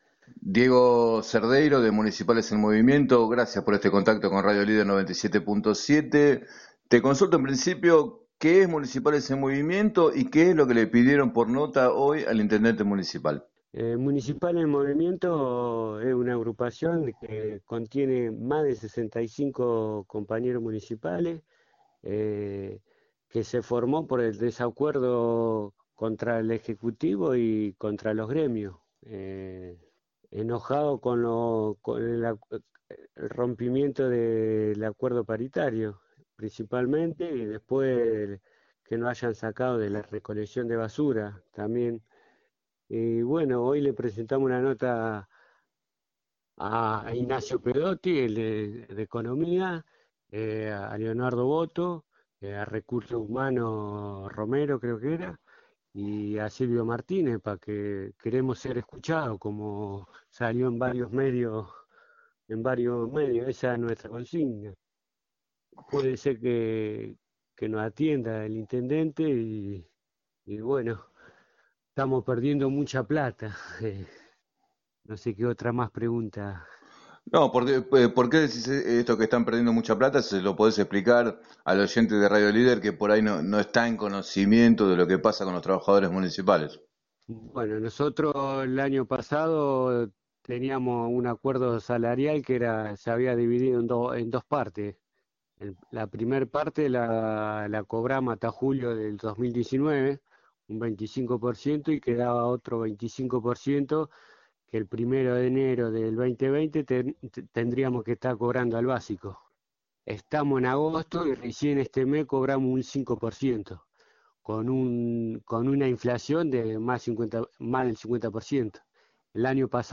En diálogo con Radio Líder 97.7